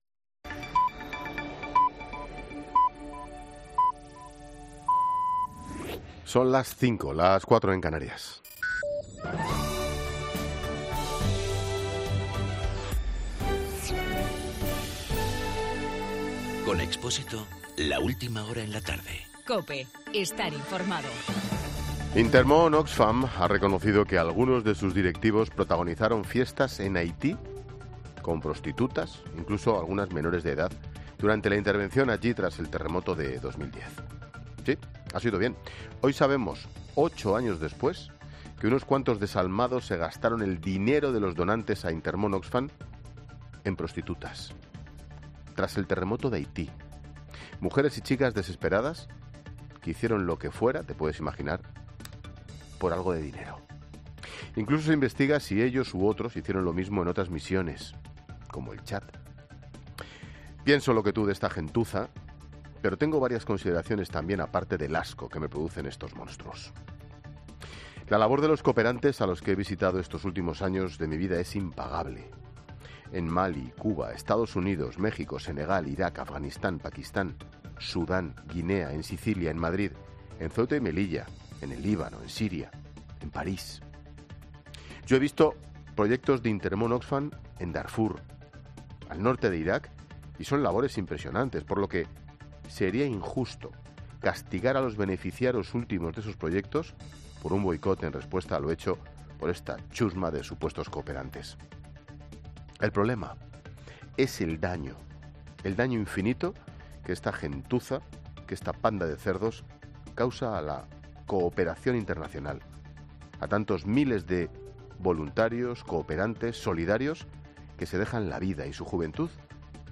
Monólogo de Expósito